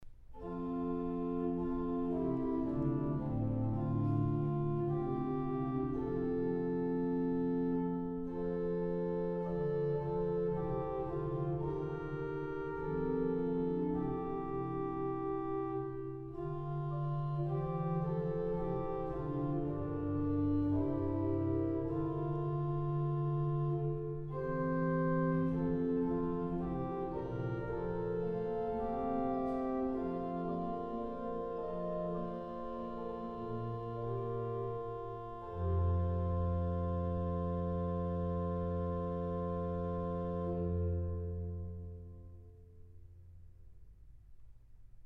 8 teilen (Holdich orgel):